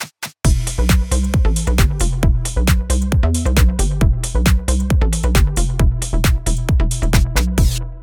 メンバーは白鍵7音ですけども、各カタマリが全てミで始まり、最低音も最高音もミで、終盤にミの連打があって、終わりもミ。
ミが中心となったこの音組織は、メジャーキーともマイナーキーとも微妙に違った独特な雰囲気を持っています。
m3-tonality-phrygian.mp3